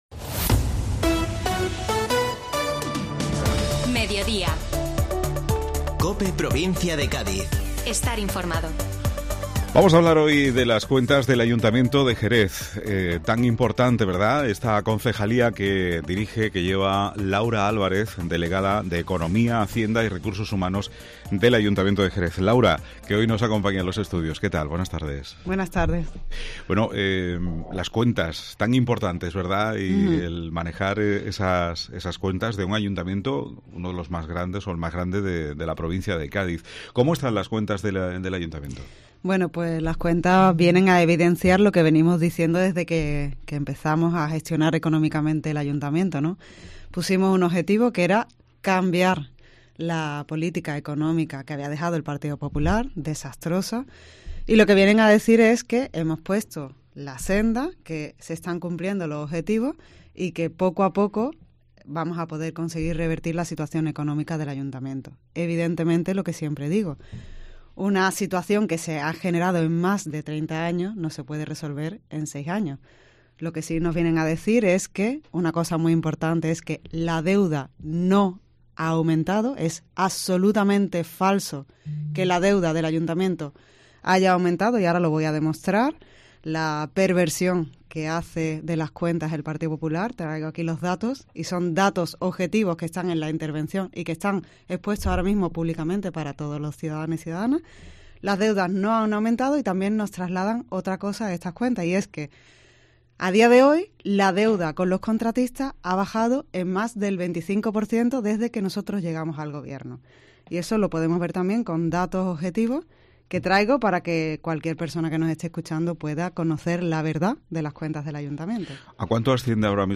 Laura Álvarez, Delegada de economía, hacienda y recursos humanos del Ayuntamiento de Jerez habla del trabajo del equipo de gobierno para revertir la situación económica del Ayuntamiento y cambiar la política económica que había dejado el PP.